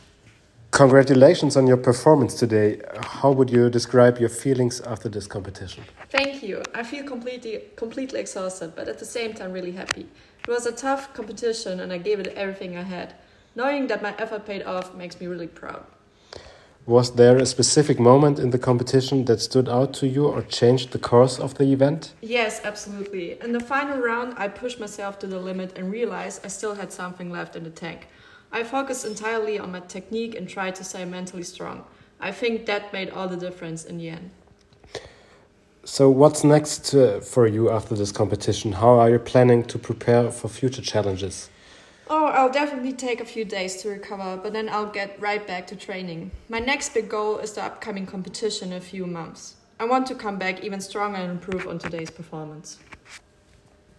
Interview title: [Competition], [Athlete name], [Date]
Interviewer (I): [Name]
Athlete (A): [Name]